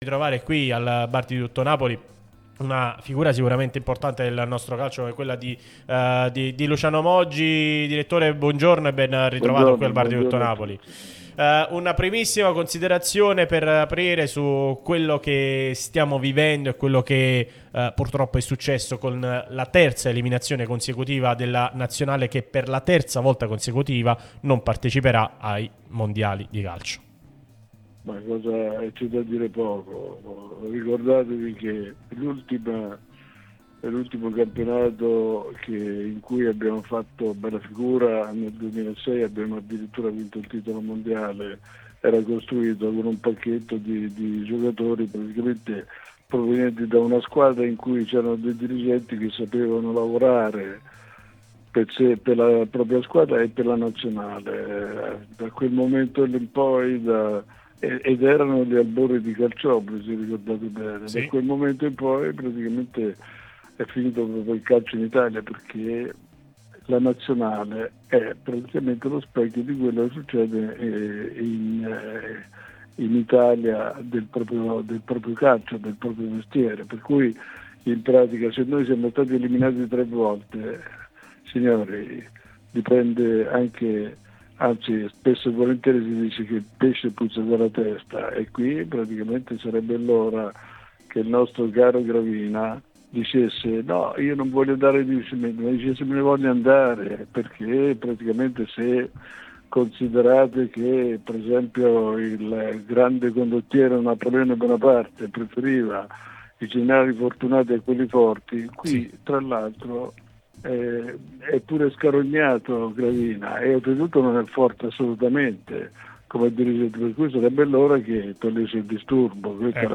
Luciano Moggi è intervenuto durante Il Bar di Tutto Napoli sulla nostra Radio Tutto Napoli,